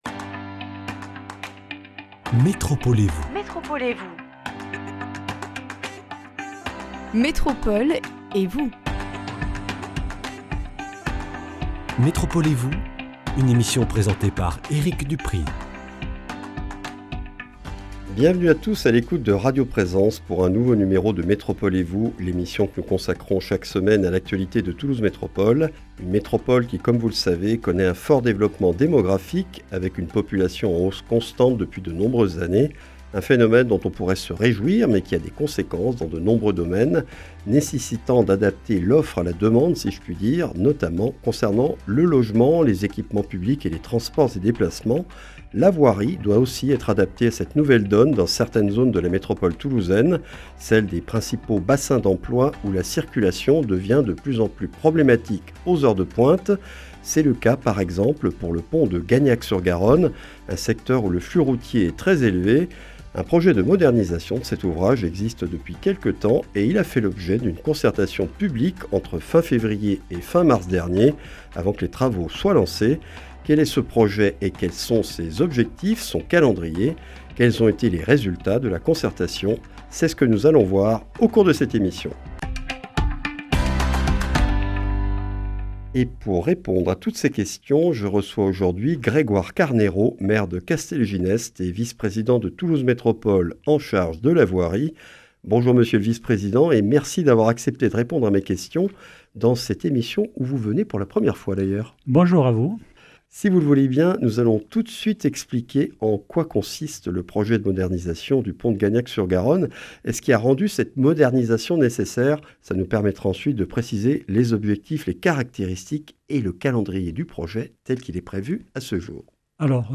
Grégoire Carneiro, maire de Castelginest et vice-président de Toulouse Métropole chargé de la voirie, nous présente les caractéristiques et les objectifs de ce projet, puis commente les résultats de cette concertation qui a recueilli 764 contributions.